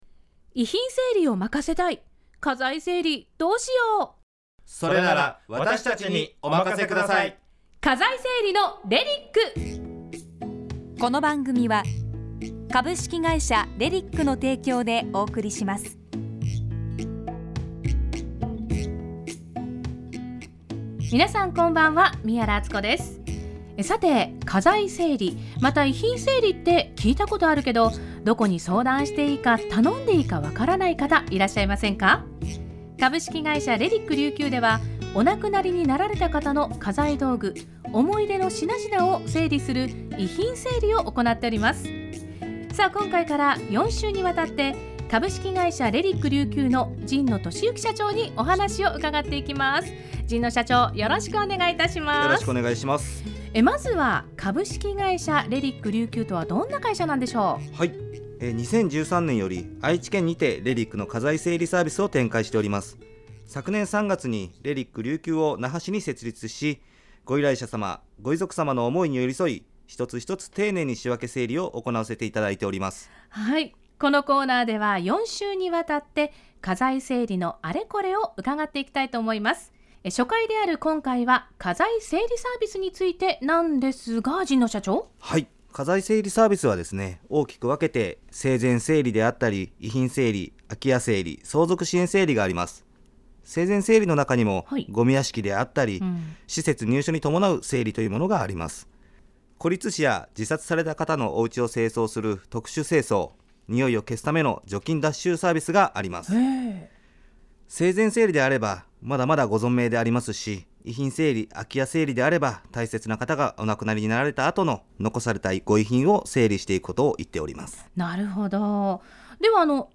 今回は、沖縄県にあるRBCラジオ様にて放送された、レリック琉球のラジオ番組の内容をお伝えさせて頂きます！